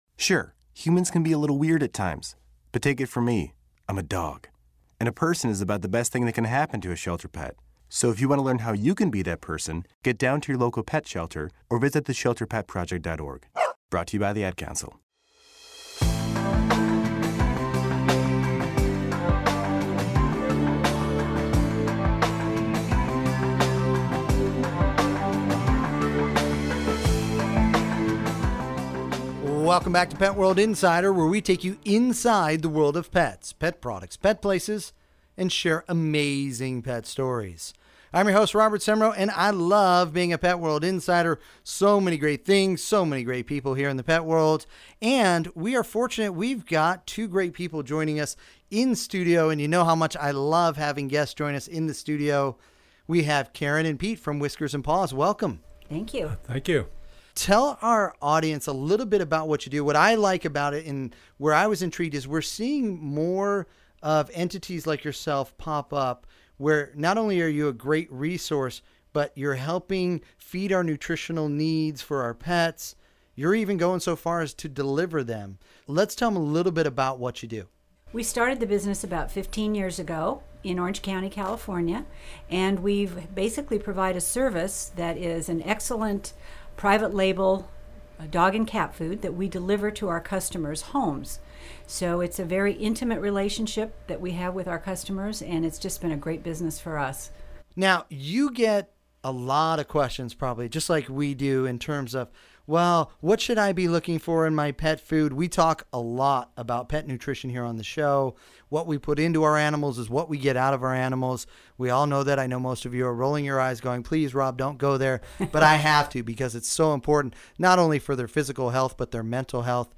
On this Pet World Radio Segment we sit down with Whiskers & Paws Pet Food, to discuss pet food, pet nutrition, healthy ingredients and so much more.